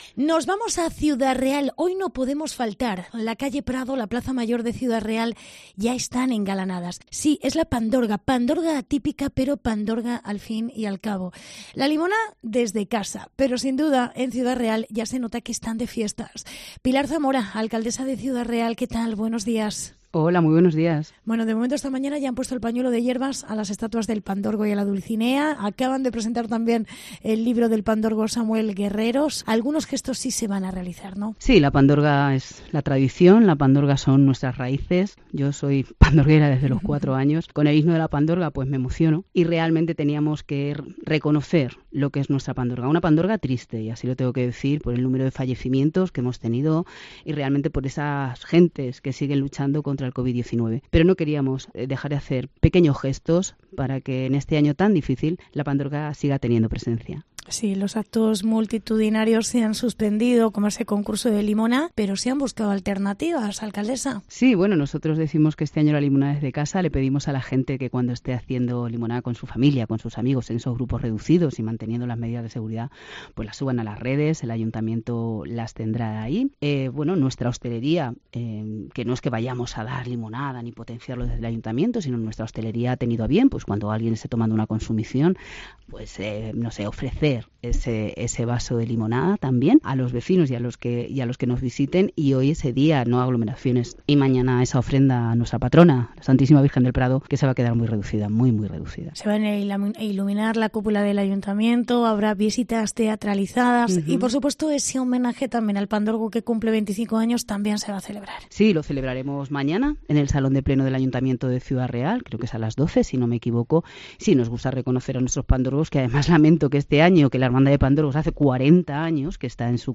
Pilar Zamora en los estudios de COPE Ciudad Real